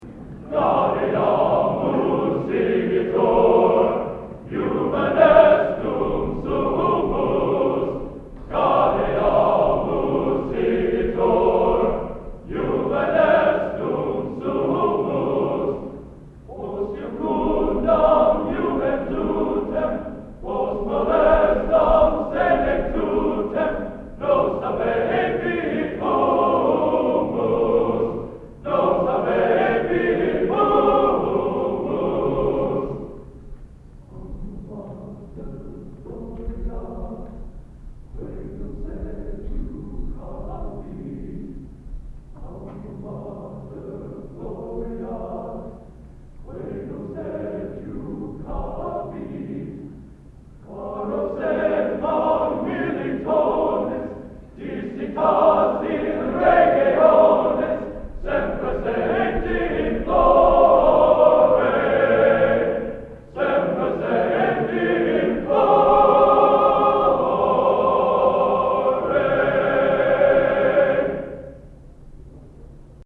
Location: West Lafayette, Indiana
Genre: Collegiate | Type: